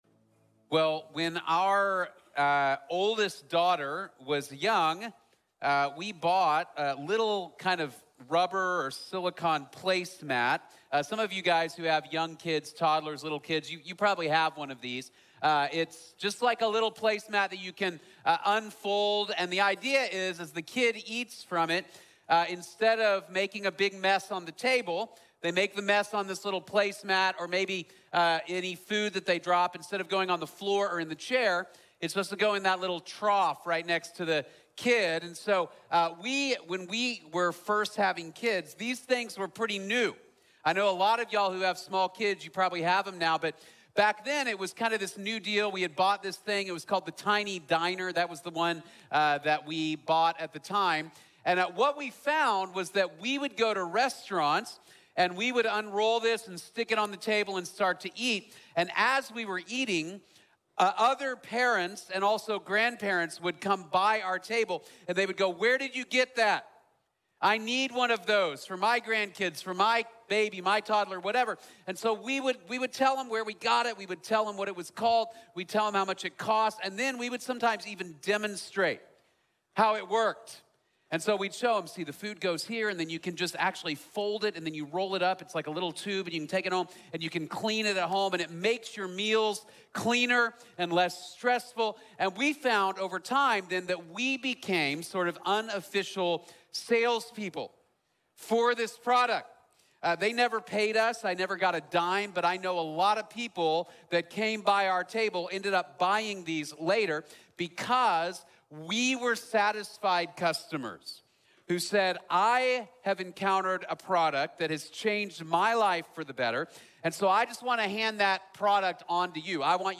The Goal of Discipleship | Sermon | Grace Bible Church